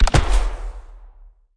Regular Explode Sound Effect
Download a high-quality regular explode sound effect.
regular-explode-2.mp3